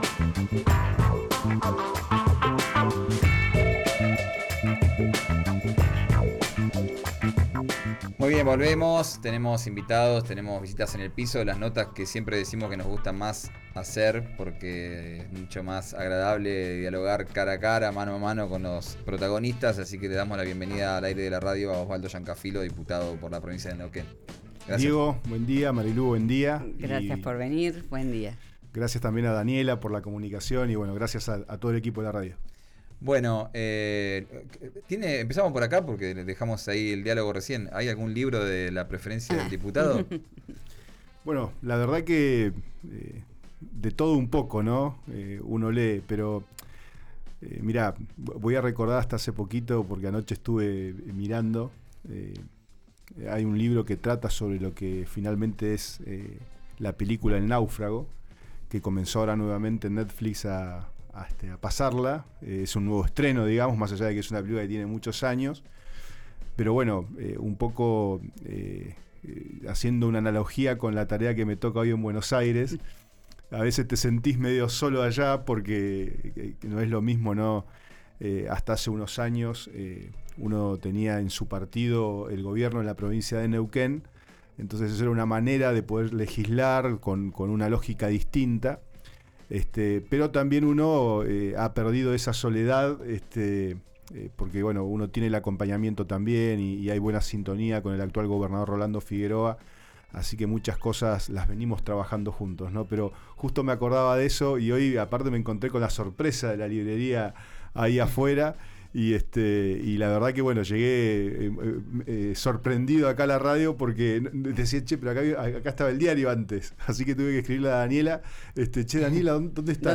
El diputado del MPN por la provincia de Neuquén, Osvaldo Llancafilo, visitó los estudios de RÍO NEGRO RADIO para conversar sobre los ejes principales de su gestión en la Cámara de Diputados de la Nación.